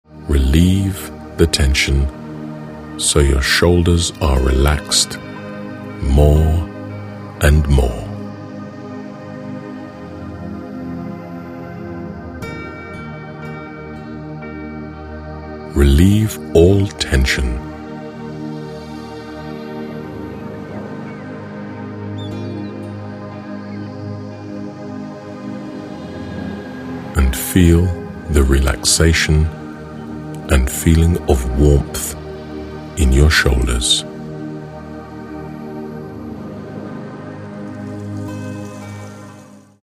This Audiobook is a guide for your self-studies and learning.